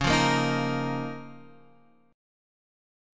Db6add9 Chord